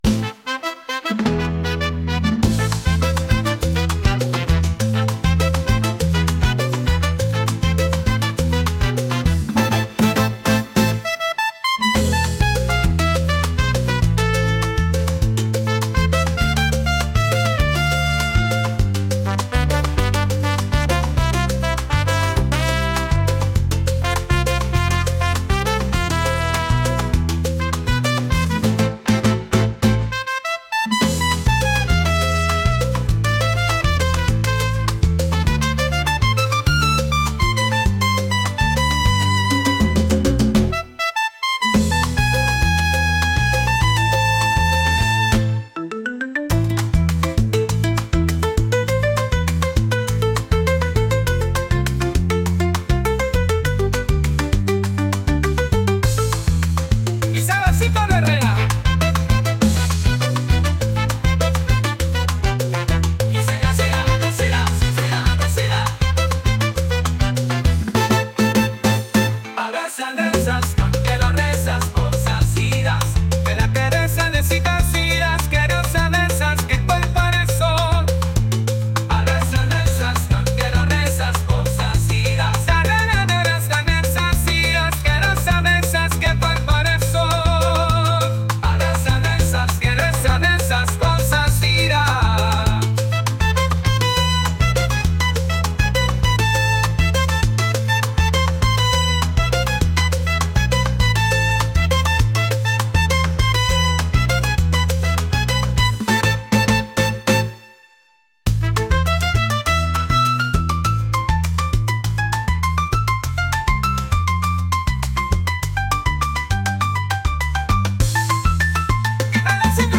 latin | energetic